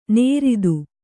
♪ nēridu